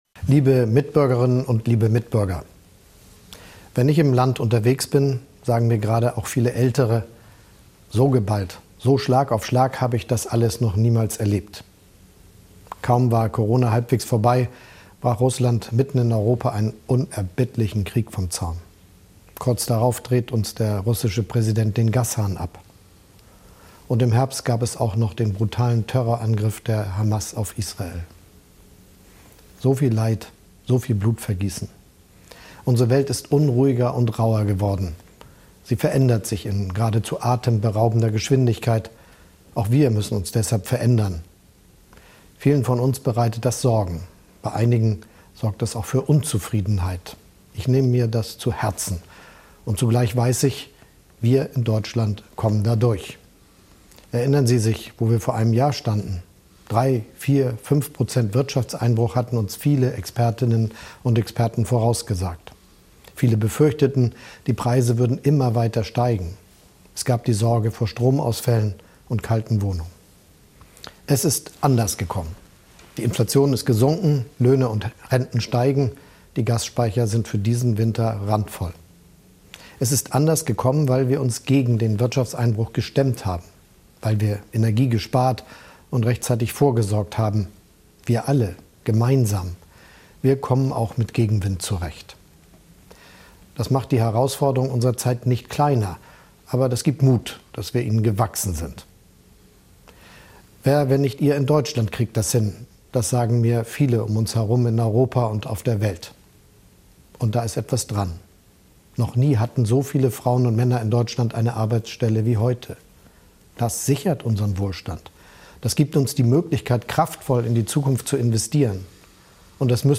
Neujahrsansprache Olaf Scholz 2023/24